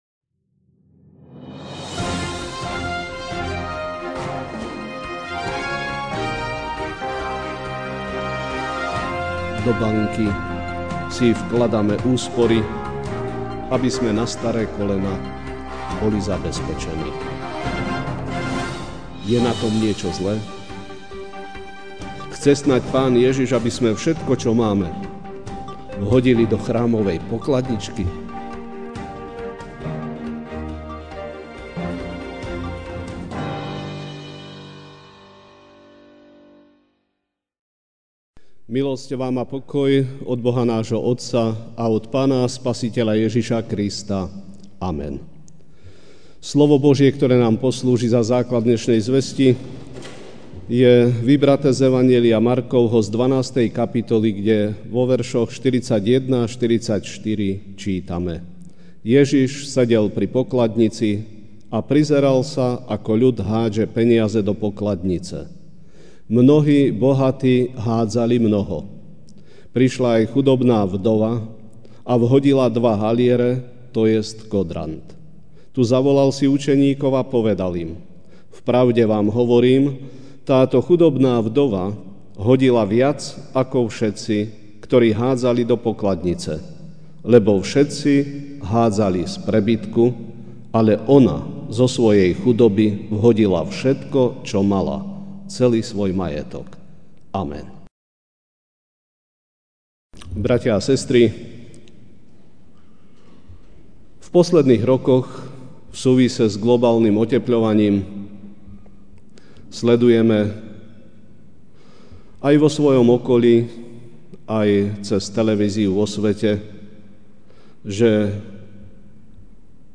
Večerná kázeň: Sloboda (Mk. 12, 41-44) Ježiš sedel pri pokladnici a prizeral sa, ako ľud hádže peniaze do pokladnice.